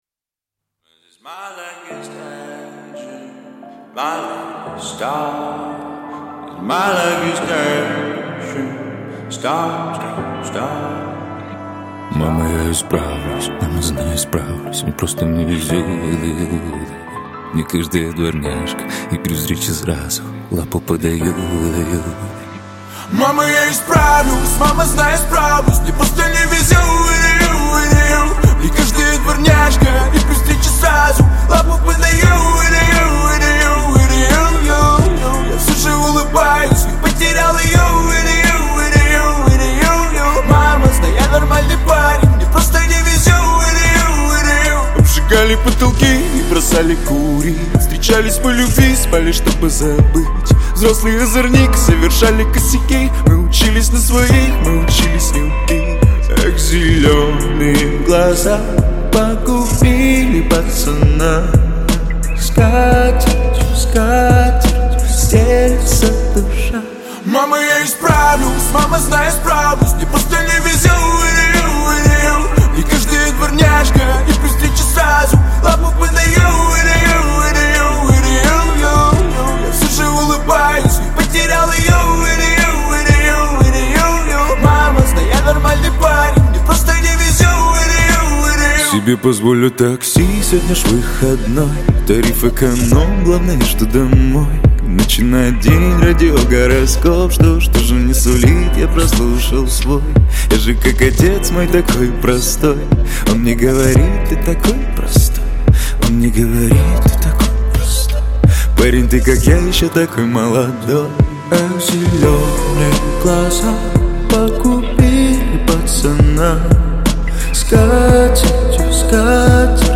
Жанр: Поп-музыка / Хип-хоп / Русский рэп